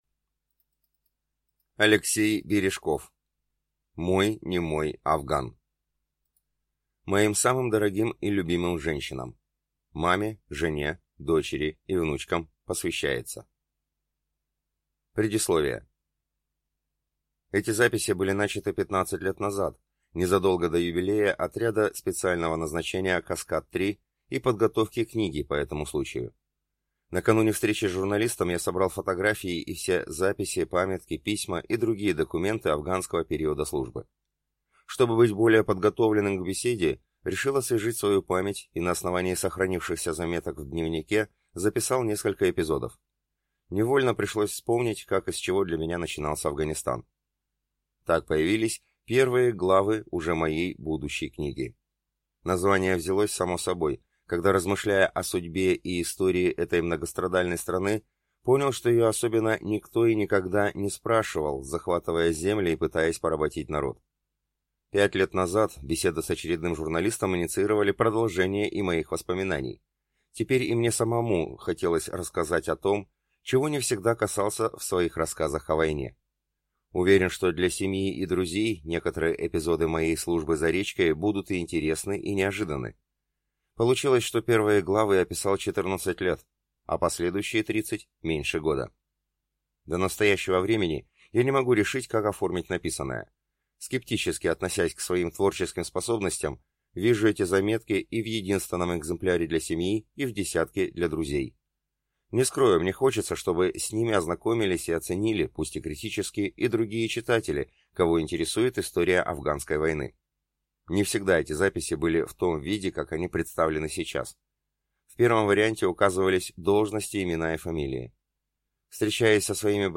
Аудиокнига Мой немой Афган | Библиотека аудиокниг